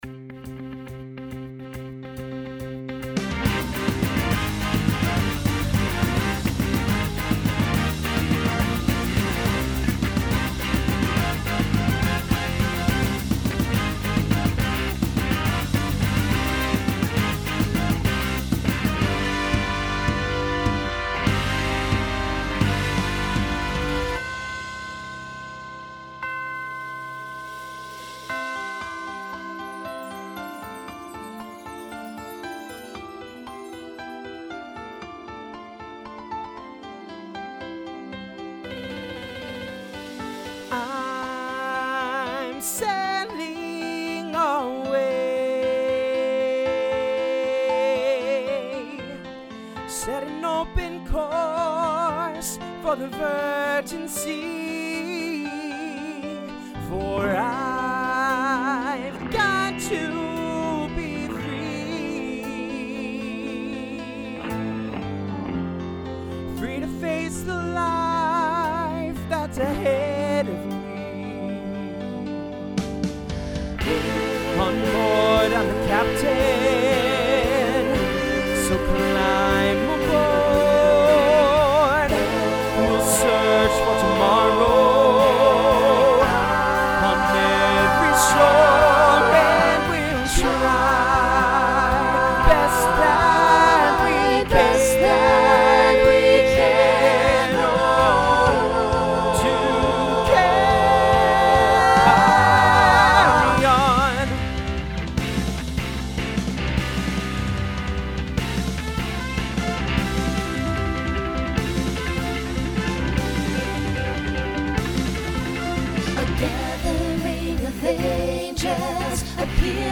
Rock Instrumental combo
Voicing SATB